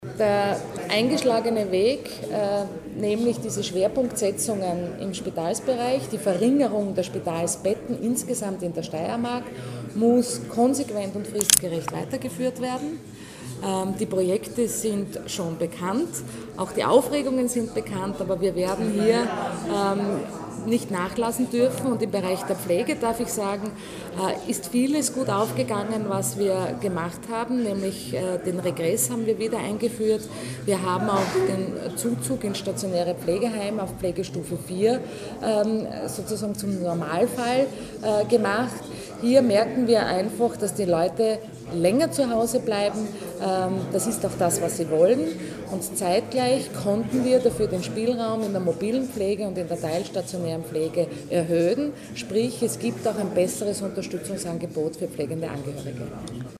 O-Ton: Budgetpräsentation Edlinger-Ploder und Schrittwieser
Oktober 2012).-  Heute (11.10.2012) präsentierten die beiden Landesräte Kristina Edlinger-Ploder und Siegfried Schrittwieser im Medienzentrum Steiermark ihre Ressortdoppelbudgets für die Jahre 2013 und 2014.